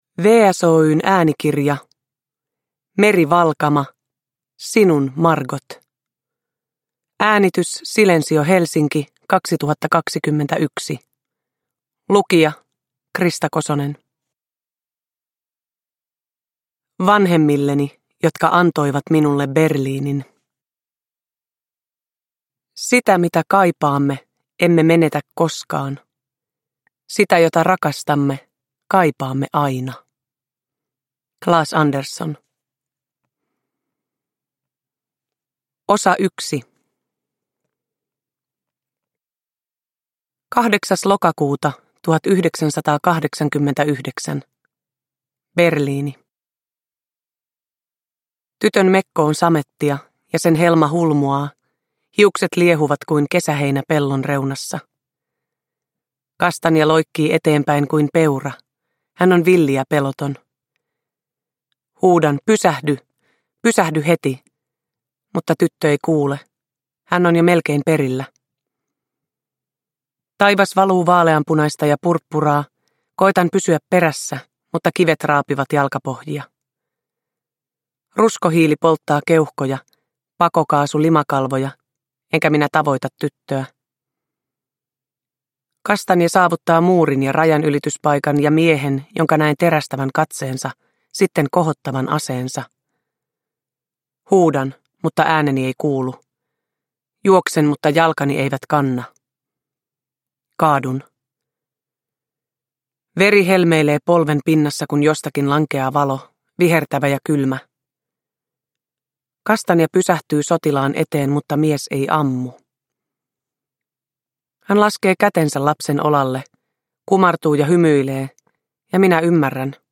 Uppläsare: Krista Kosonen